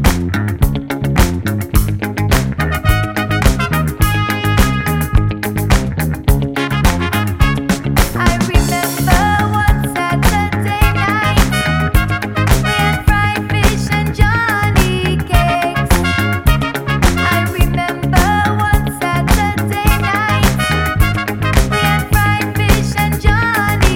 no Backing Vocals Disco 4:02 Buy £1.50